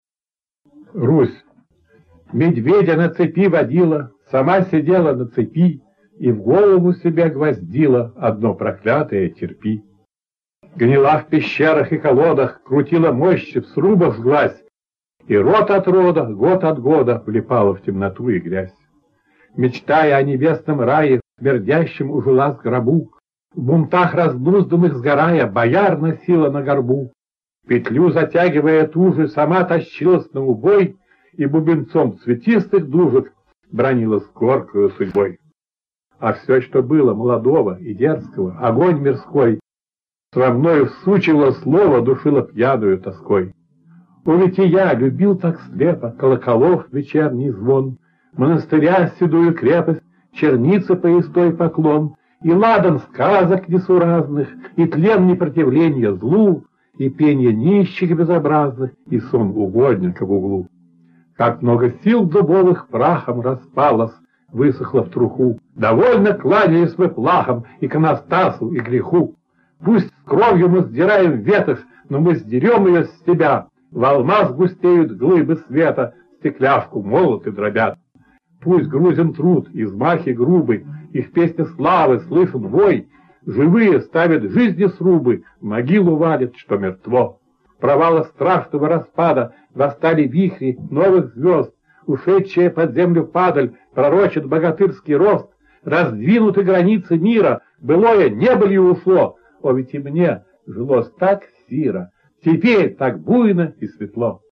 1. «Сергей Городецкий – Русь (читает автор)» /
sergej-gorodetskij-rus-chitaet-avtor